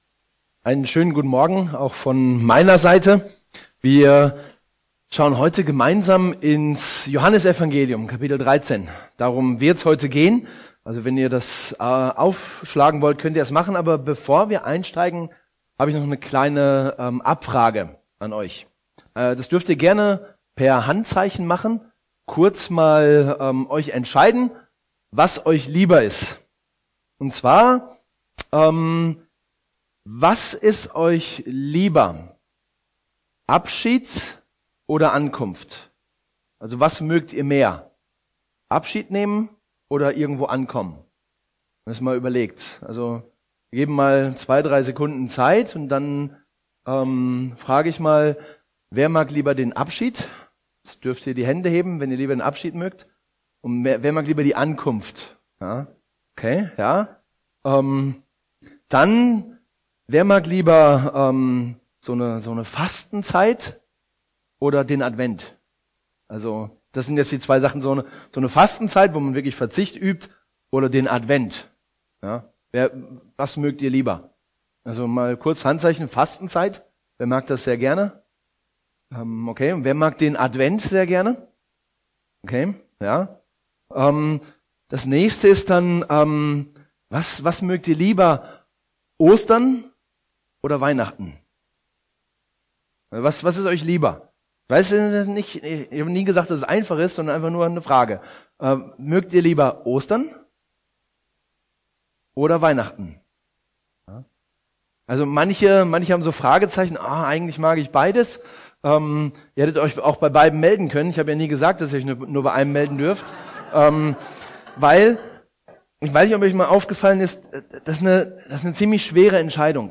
Jesus dient uns ~ Predigten aus der Fuggi Podcast